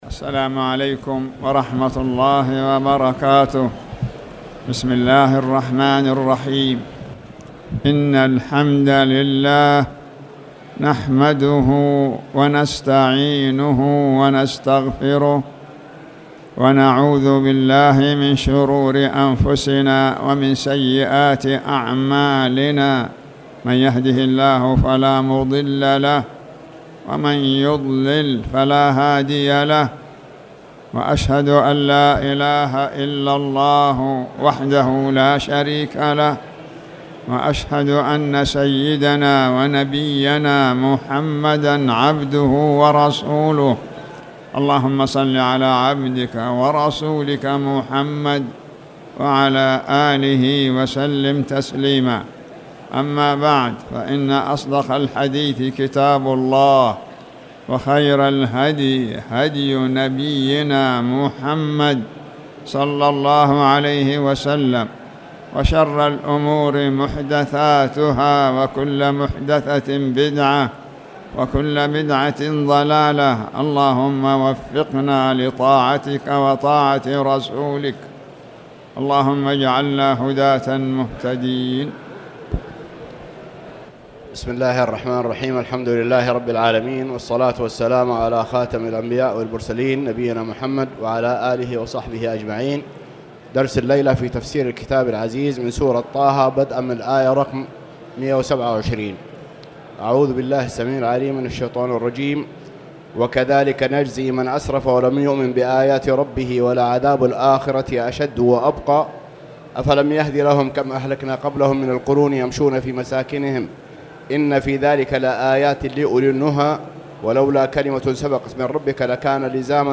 تاريخ النشر ٢١ شعبان ١٤٣٨ هـ المكان: المسجد الحرام الشيخ